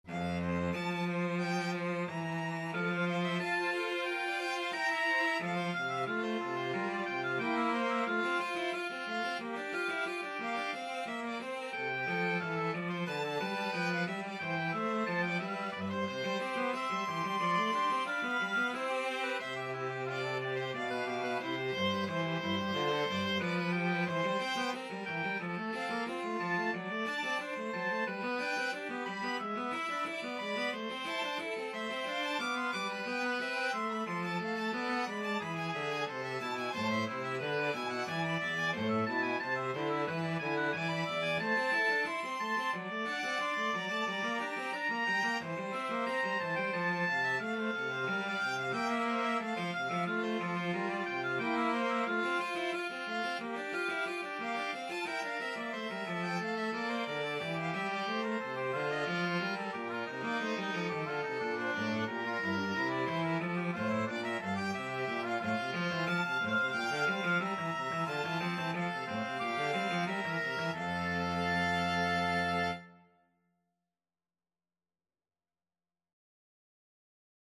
OboeViolinCello
4/4 (View more 4/4 Music)
F major (Sounding Pitch) (View more F major Music for Mixed Trio )
Classical (View more Classical Mixed Trio Music)